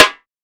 Snares
SNARE.67.NEPT.wav